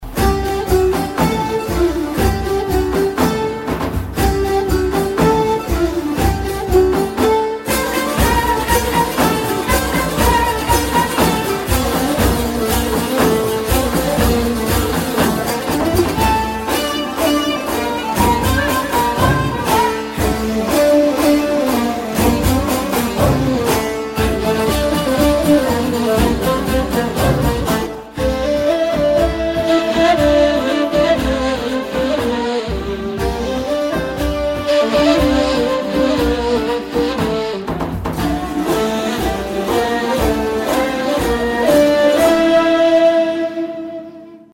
زنگ موبایل بی کلام و شاد